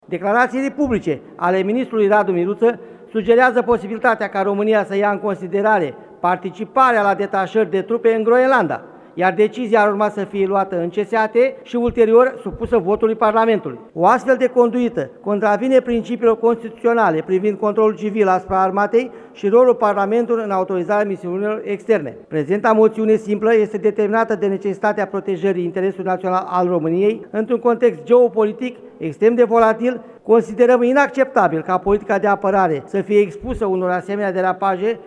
Senatorul AUR, Eugen Șipoș: „O astfel de conduită contravine principiilor constituționale privind controlul civil asupra armatei și rolul Parlamentului în exercitarea misiunilor externe”